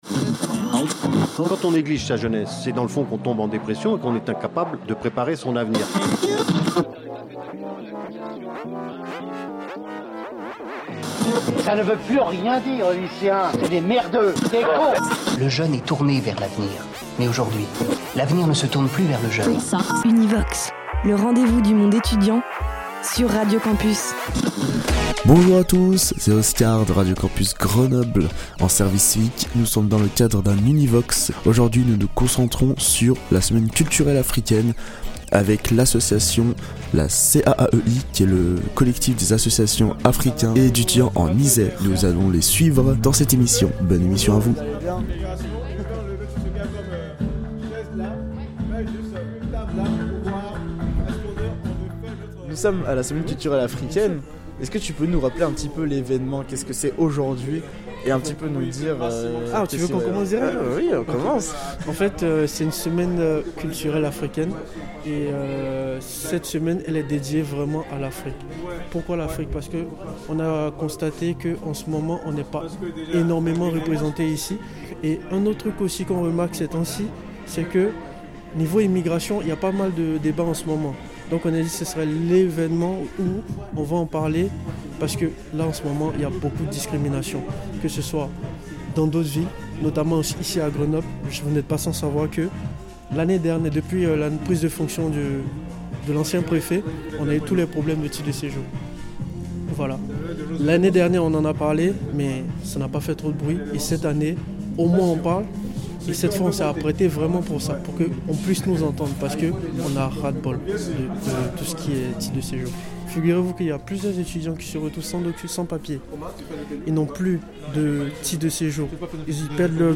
La 19e édition des Assises du journalisme a ouvert ses portes début avril au Palais des congrès de Tours. Les radios Campus ont relancé La Fabrique. Ouvert durant trois jours, composé de deux studios radios entièrement équipés, 30 personnes à l'ouvrage, La Fabrique est un espace de production et d’échange entièrement dédié aux médias associatifs de proximité.